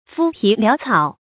肤皮潦草 fū pǐ liǎo cǎo 成语解释 形容不扎实，不仔细。